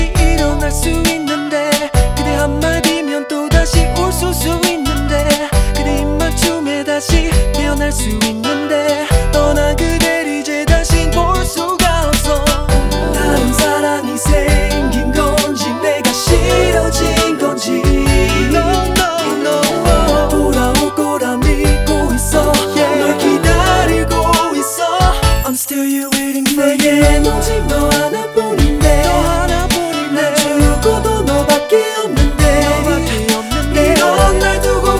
Hip-Hop Rap Pop K-Pop
Жанр: Хип-Хоп / Рэп / Поп музыка